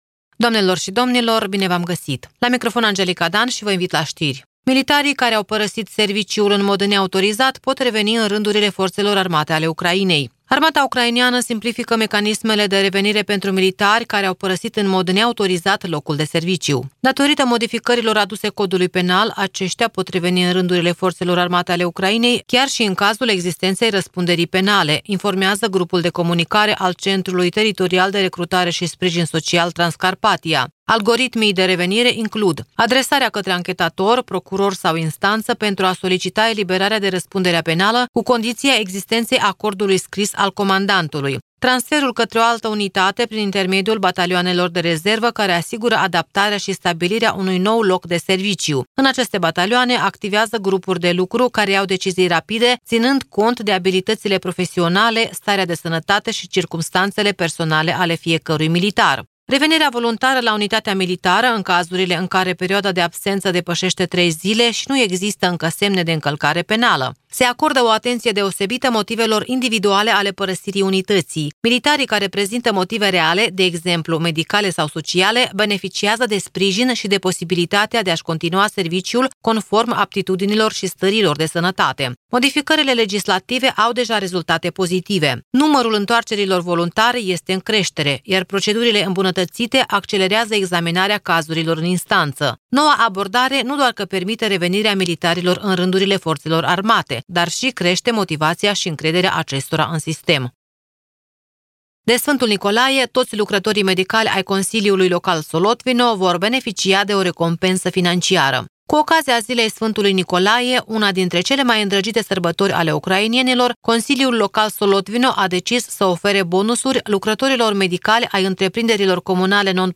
Știri de la Radio Ujgorod.